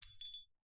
Звуки гвоздей, шурупов
Маленький металлический винт или гвоздь упал на бетон